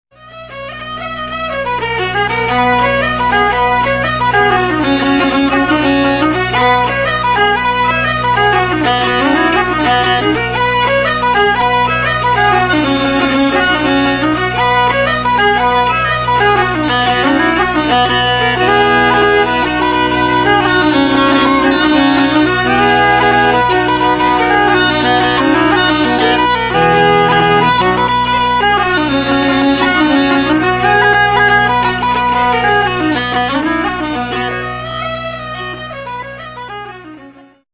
The music on this CD is played in the pitches of B and Bb.
Thus the sound is mellow and smooth.